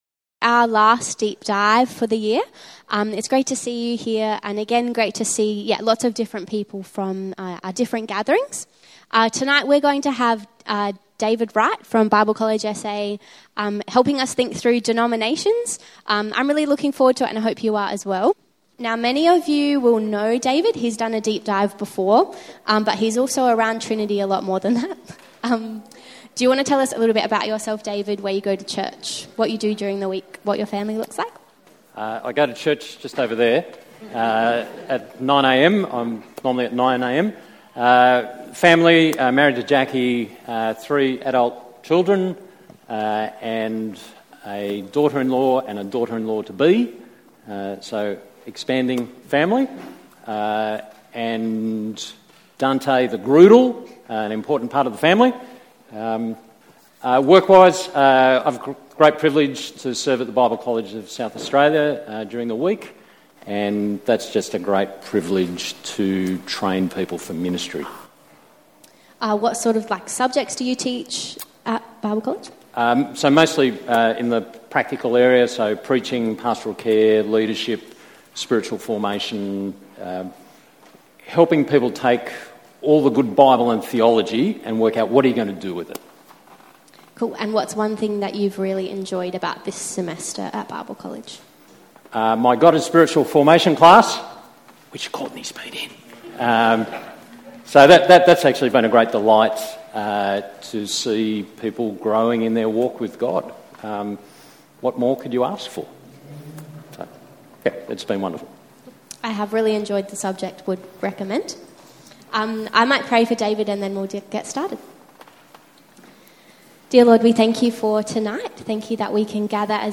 will speak on this topic andwe will discuss together.